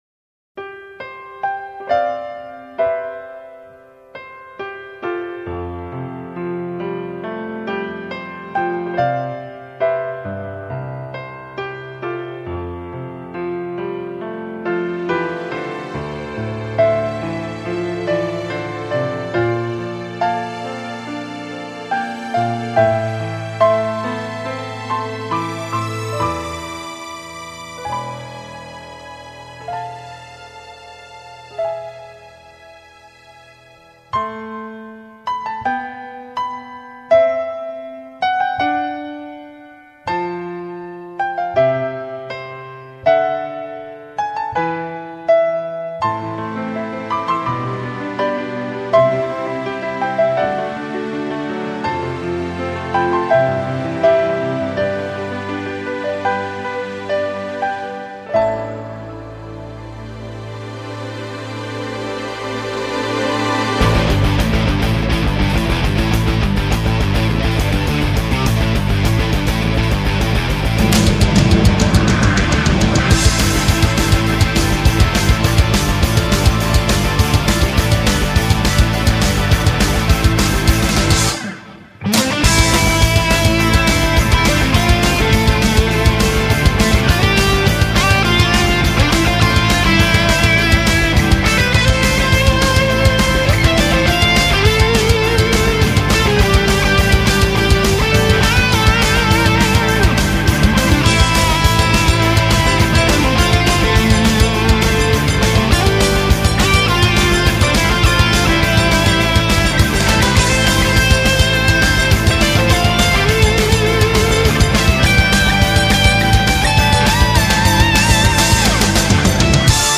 메인 테마는 같지만, 몇몇 버전은 인트로가 완전 다른걸로 알고 있습니다.